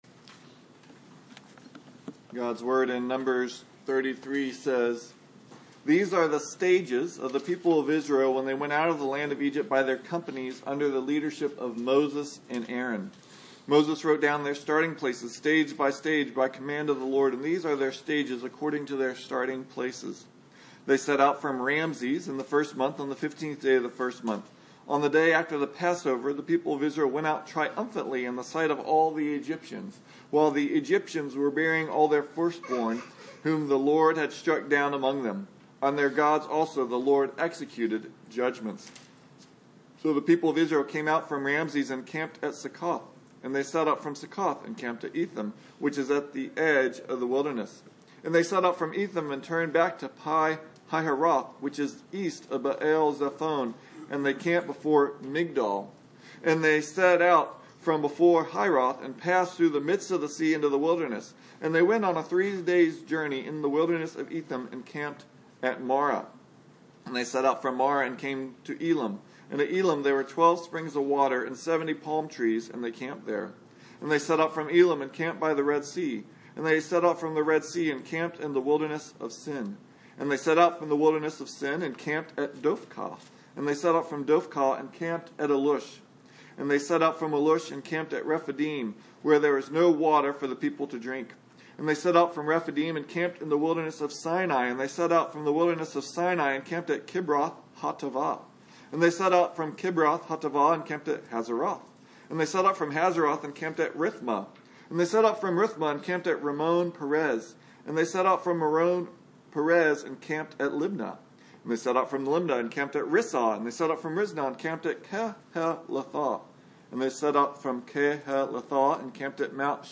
Service Type: Sunday Morning Moses lists the various places where God led Israel from Egypt to the Promised Land to give Israel confidence and courage to face their future.